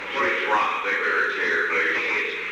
Secret White House Tapes
Location: Oval Office
The President met with an unknown person.